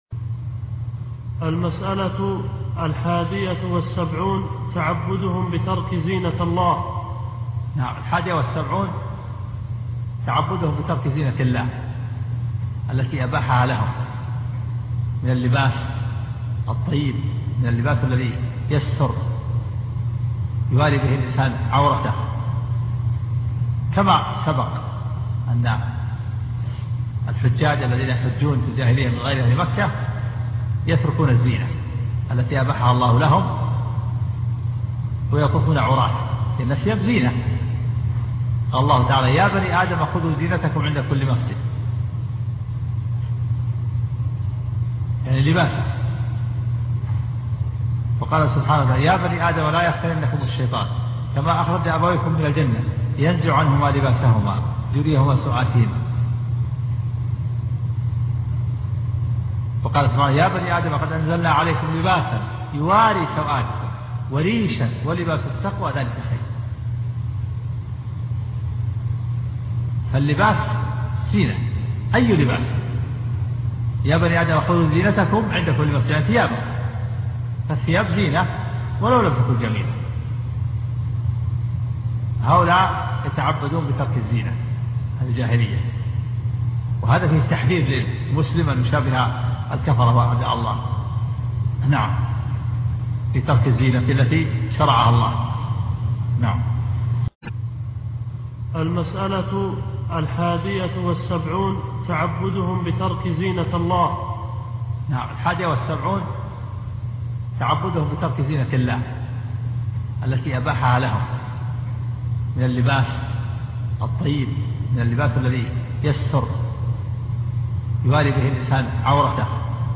الدرس 38